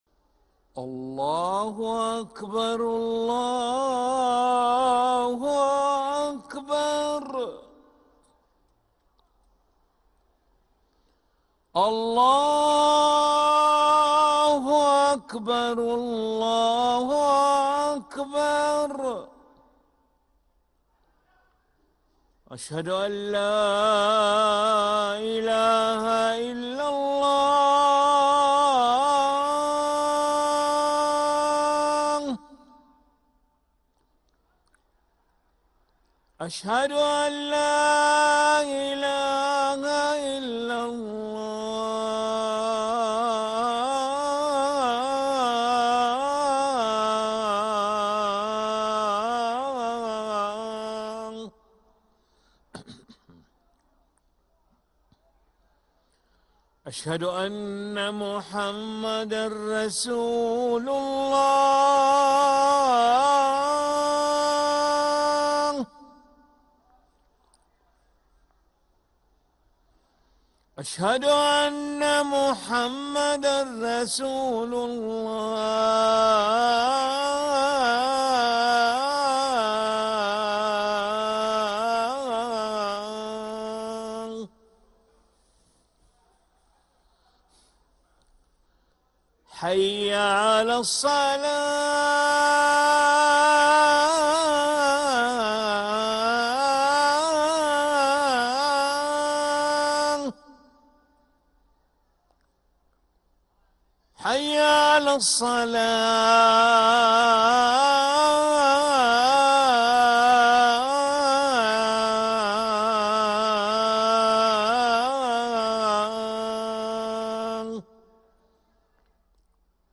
أذان العشاء للمؤذن علي ملا الخميس 23 ربيع الأول 1446هـ > ١٤٤٦ 🕋 > ركن الأذان 🕋 > المزيد - تلاوات الحرمين